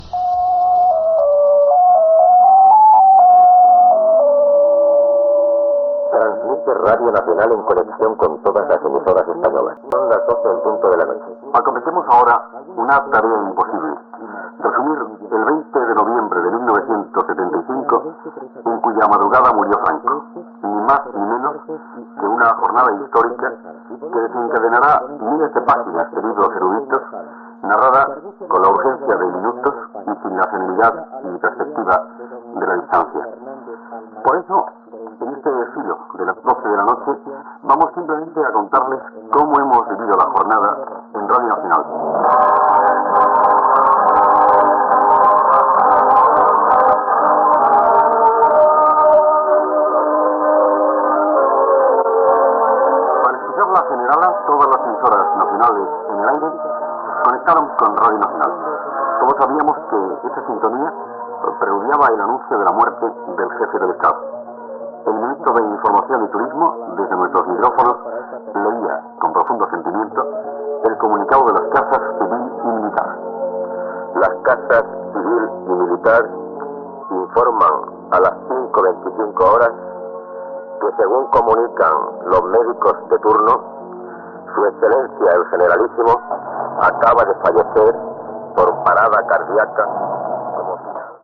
Sintonia, identificació, hora, data, resum de la jornada del dia de la mort del "caudillo" Francisco Franco, cap d'Estat espanyol.
Informatiu
Fragment extret del programa "Documentos" 70 años de RNE (2007)